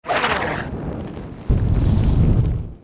SA_hot_air.ogg